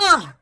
21 RSS-VOX.wav